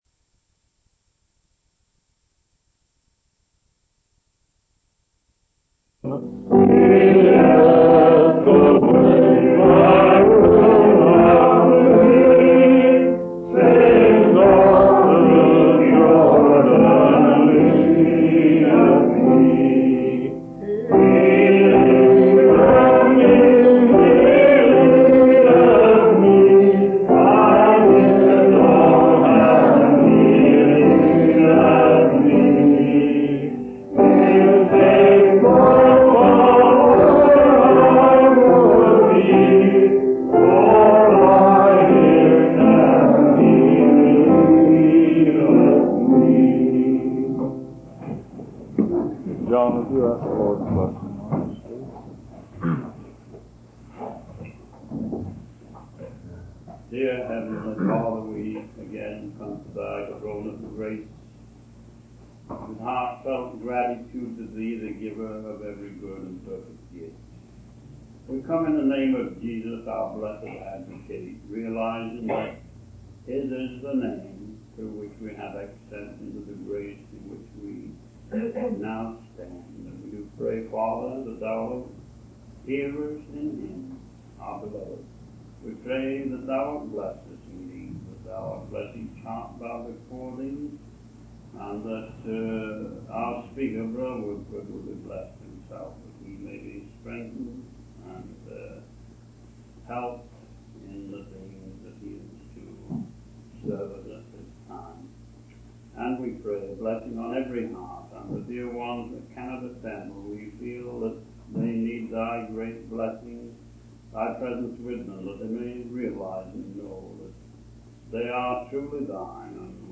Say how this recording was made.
Given in Vancuver Canada March 18, 1956